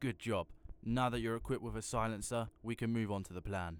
Voice Lines
good job now that youre equipped with a silencer.wav